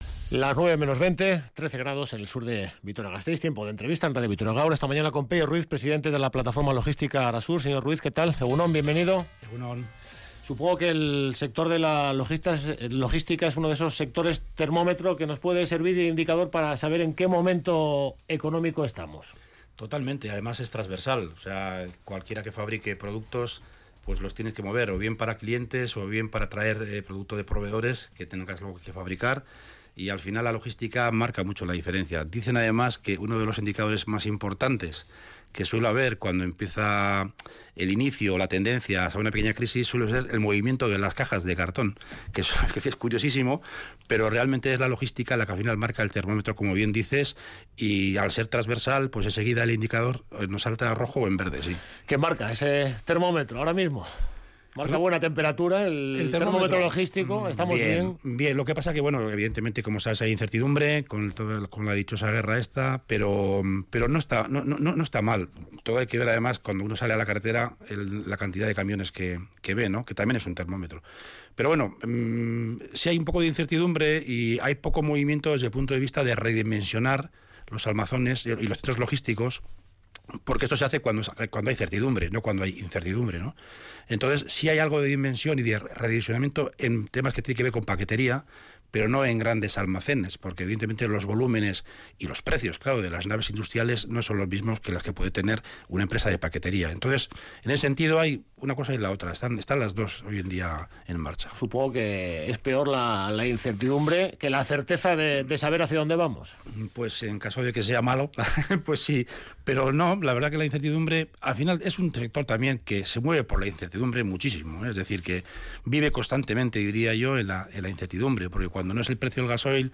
Radio Vitoria ENTREVISTA-DEL-DIA